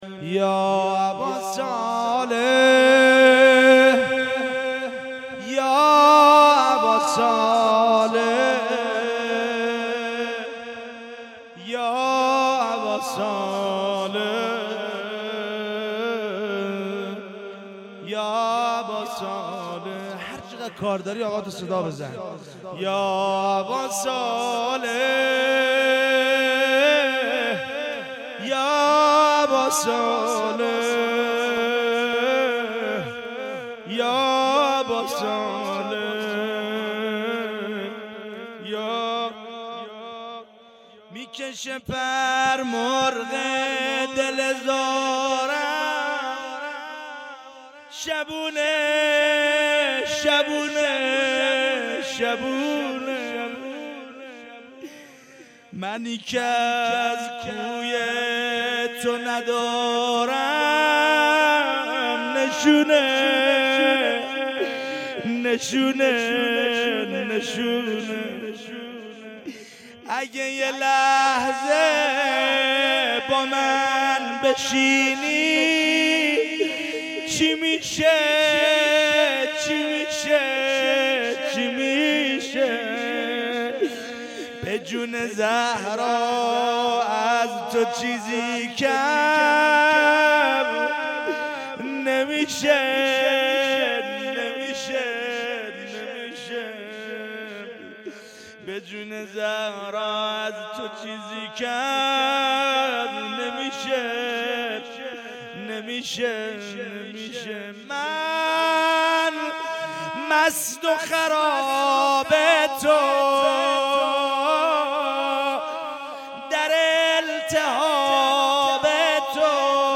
هيأت یاس علقمه سلام الله علیها
شهادت امام صادق علیه السلام-شب دوم